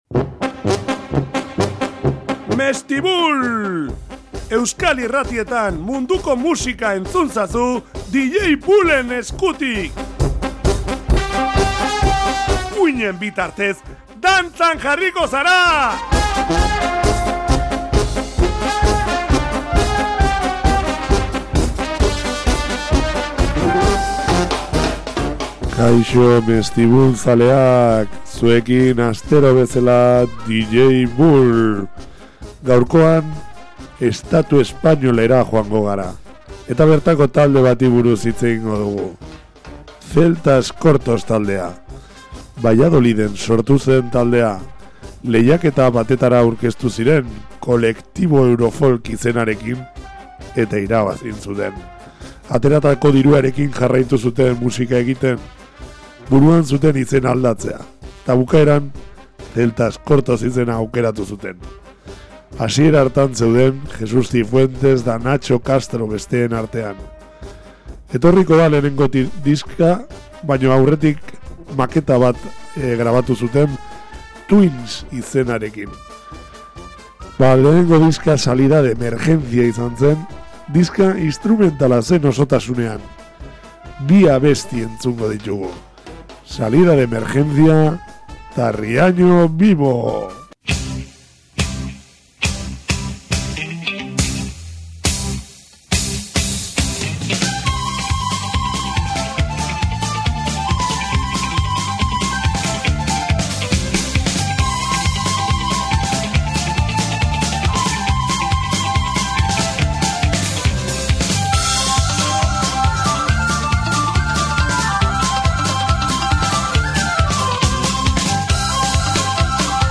Munduko musikak ezagutarazten dira dantza giroa piztuz.